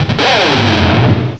Add all new cries
cry_not_krookodile.aif